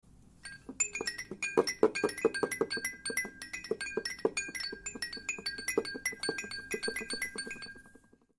На этой странице вы найдете звуки неваляшки — знакомые с детства мелодичные переливы и покачивания.
Звук деревянной игрушки, качающейся и переворачивающейся (Ванька-встанька)